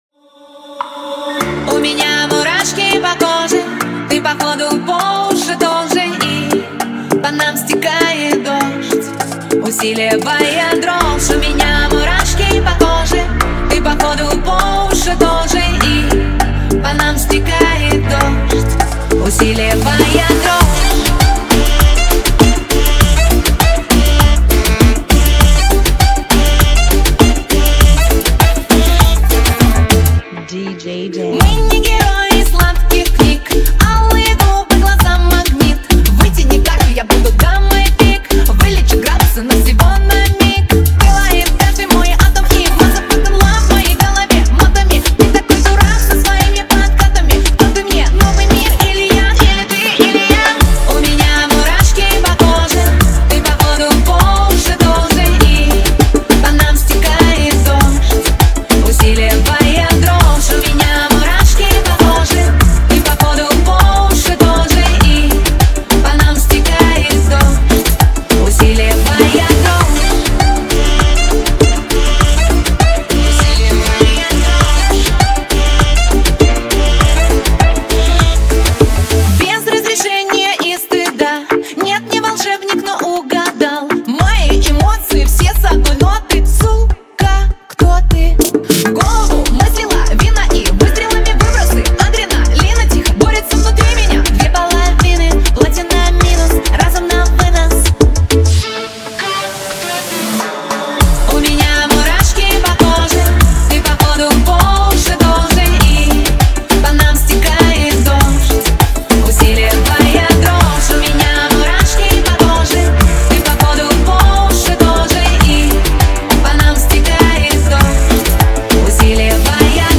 Genre: Bachata Remix